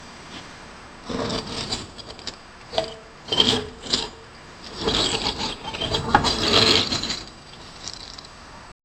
Scraping metal on barnacles (wet, gritty sounds), worms plopping into snow, and the pig's relieved snorts
scraping-metal-on-barnacl-iuqsmasw.wav